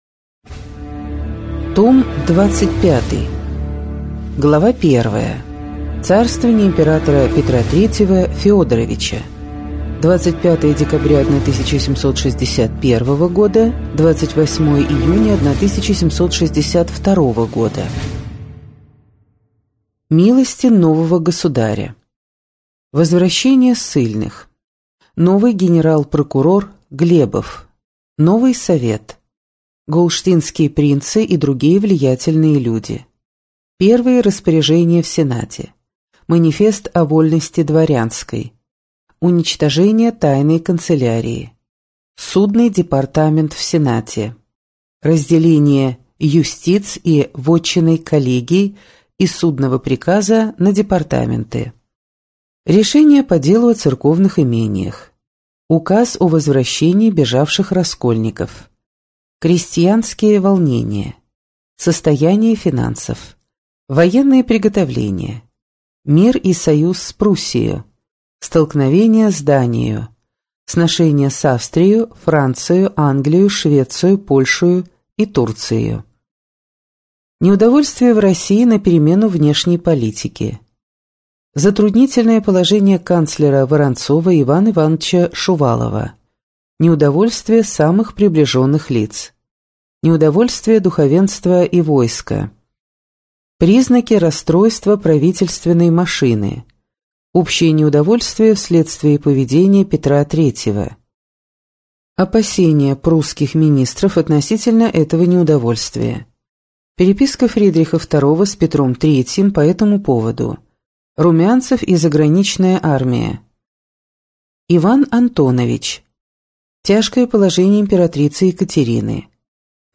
Аудиокнига История России с древнейших времен. Том 25 | Библиотека аудиокниг